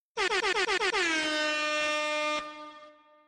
Annoying Air horn1